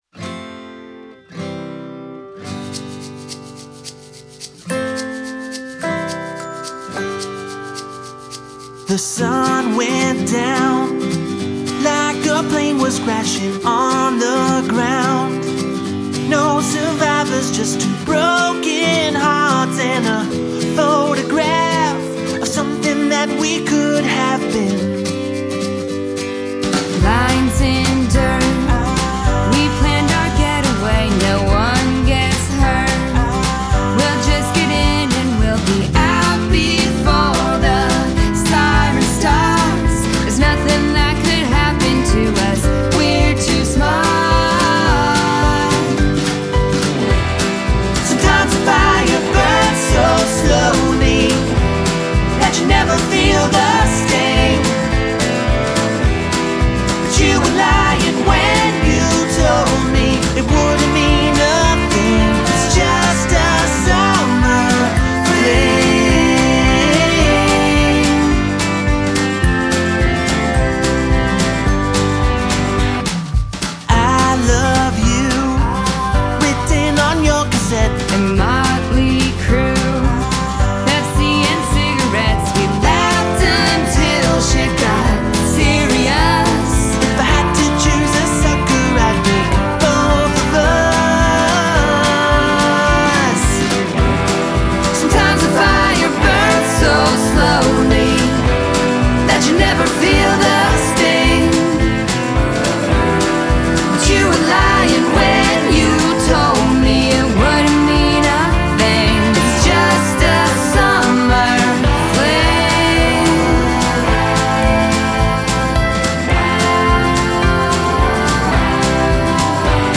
” another great acoustic swing duet